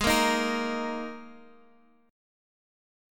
Listen to G#sus2 strummed